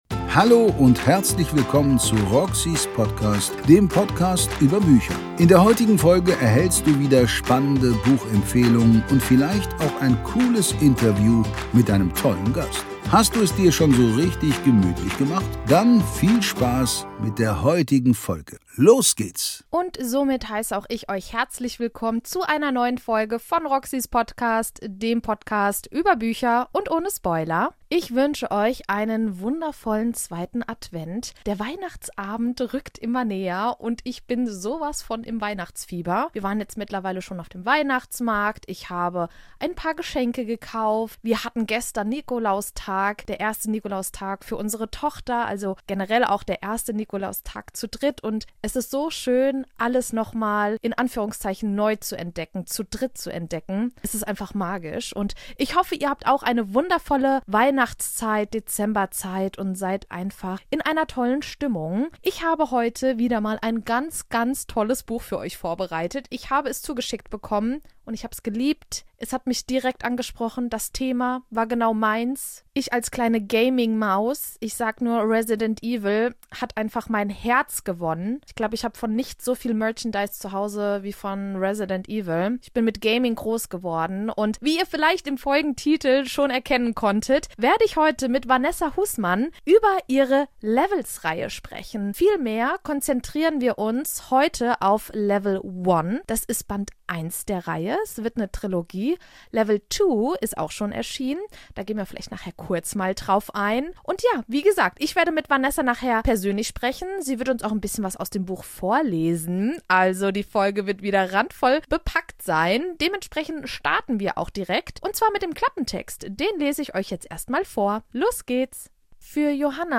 Folge #301 Gaming and Lovestory? OHJA! Interview